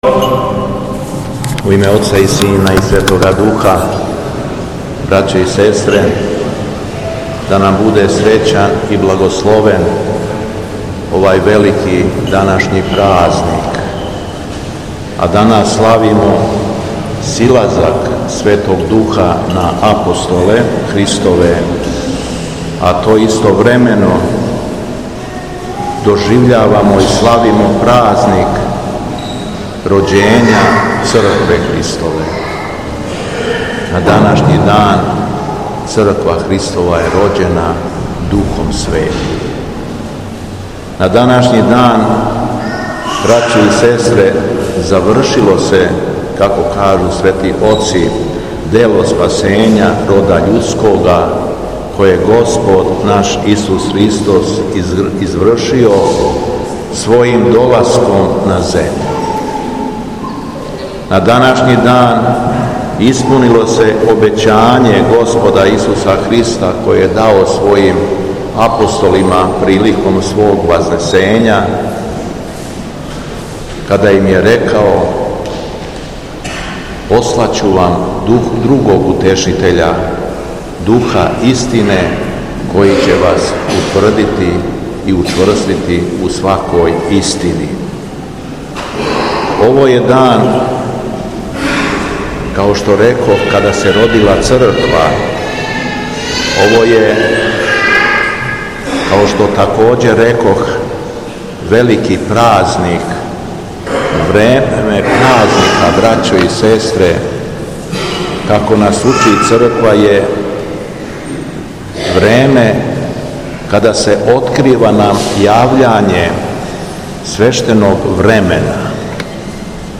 СИЛАЗАК СВЕТОГ ДУХА НА АПОСТОЛЕ – ПЕДЕСЕТНИЦА - У САБОРНОМ ХРАМУ У КРАГУЈЕВЦУ - Епархија Шумадијска
Беседа Његовог Високопреосвештенства Митрополита шумадијског г. Јована
После прочитаног јеванђелског зачала Високопреосвећени митрополит је рекао: